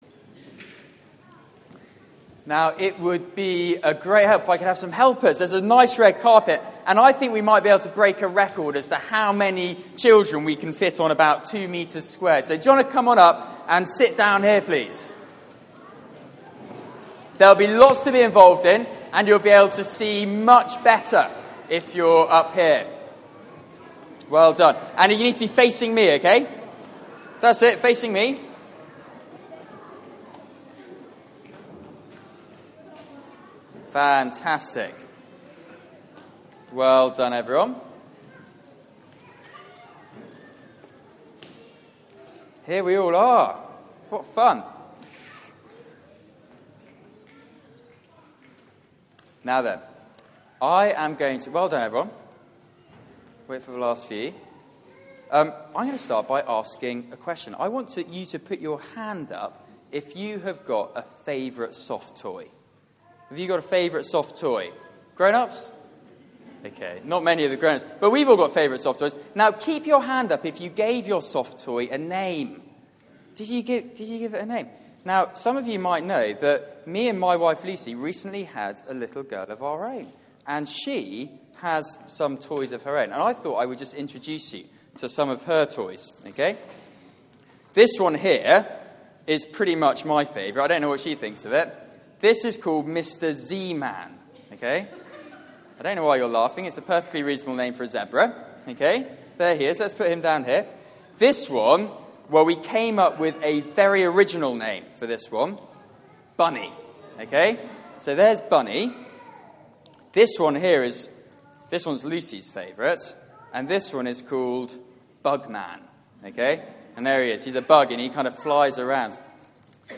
Media for 4pm Service on Sun 20th Dec 2015 16:00 Speaker
Theme: Names / Jesus- God Saves Sermon